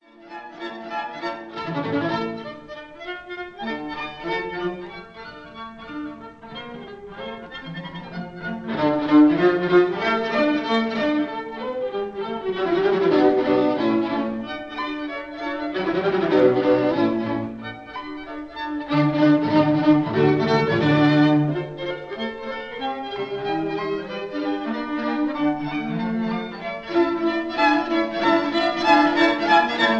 D minor
violin
viola